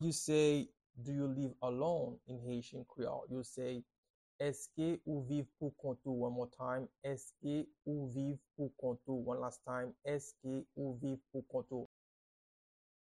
Do-you-live-alone-in-Haitian-Creole-–-Eske-ou-viv-pou-kont-ou-pronunciation-by-a-Haitian-teacher.mp3